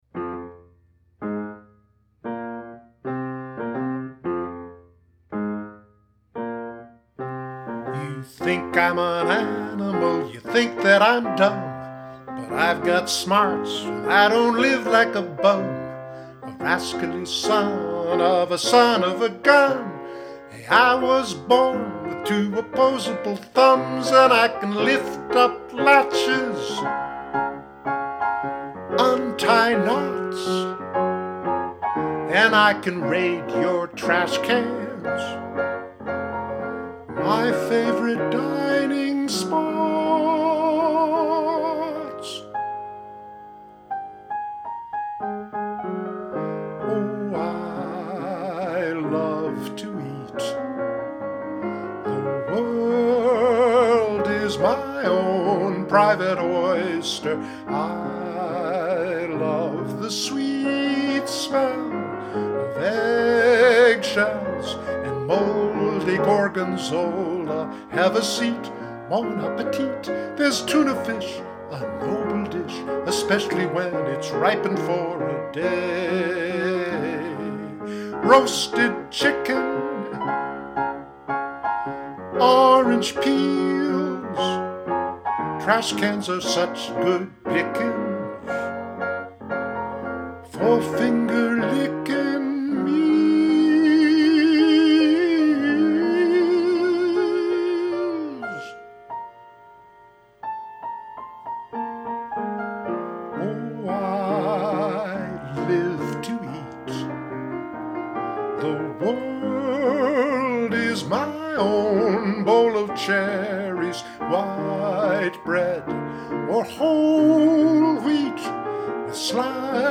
From the children’s musical, “Daniel Bandito, Raccoon”